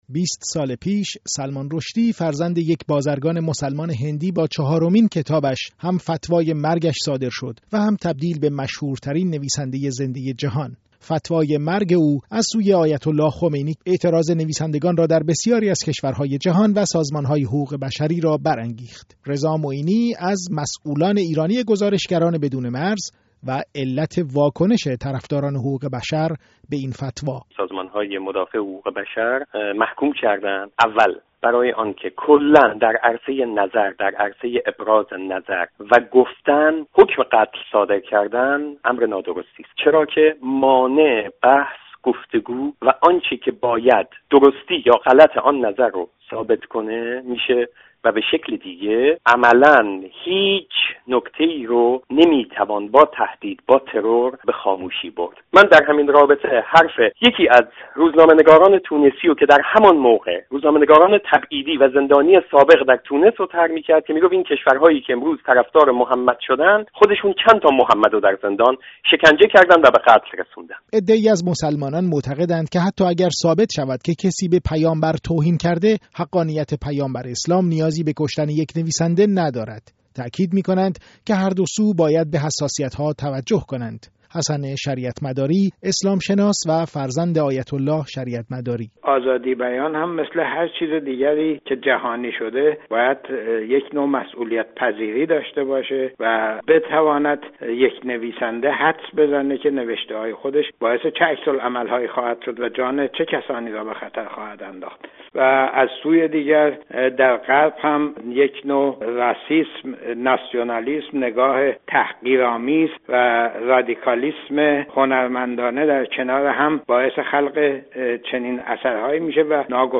گزارش رادیویی در همین زمینه